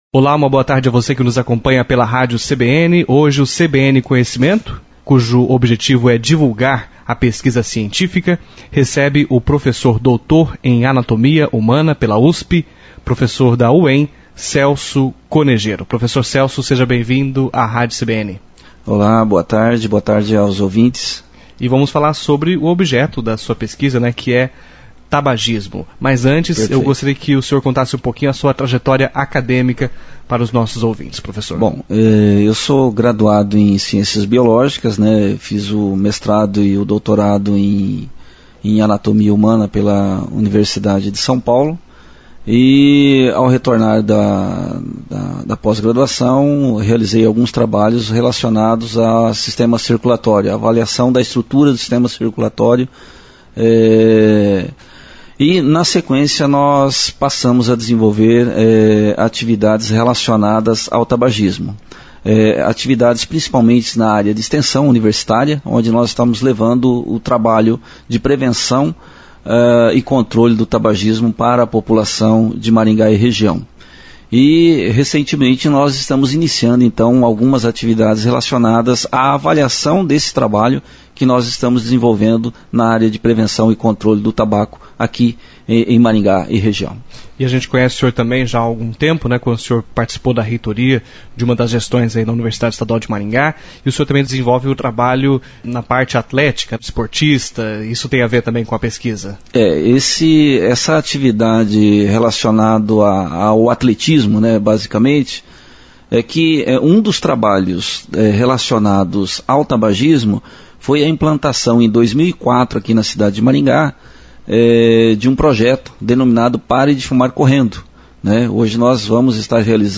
entrevista-na-cbn-parte-1